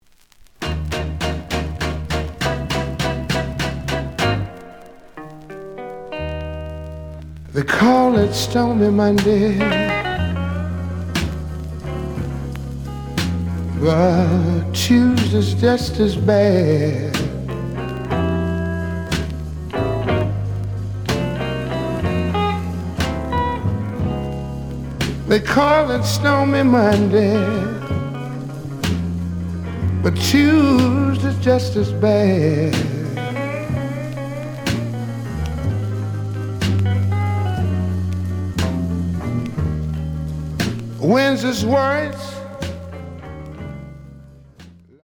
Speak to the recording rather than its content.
The audio sample is recorded from the actual item. Slight edge warp. But doesn't affect playing. Plays good.